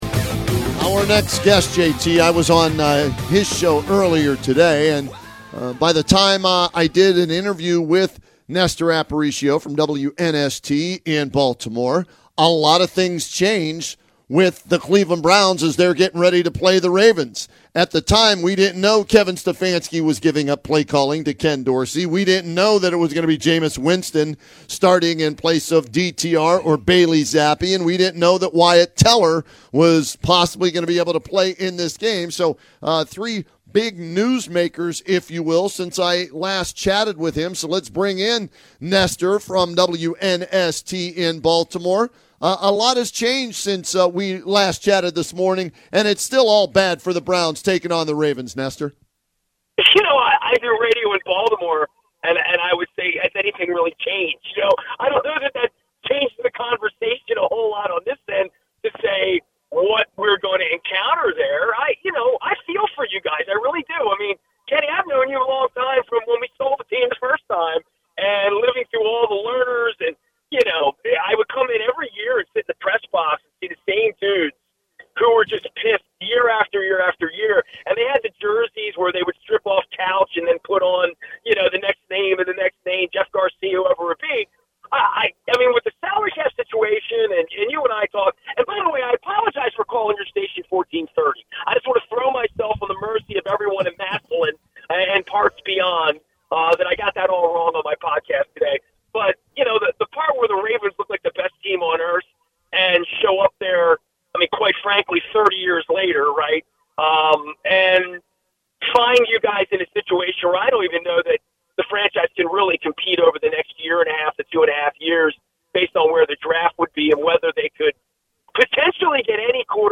A better radio guest than a host.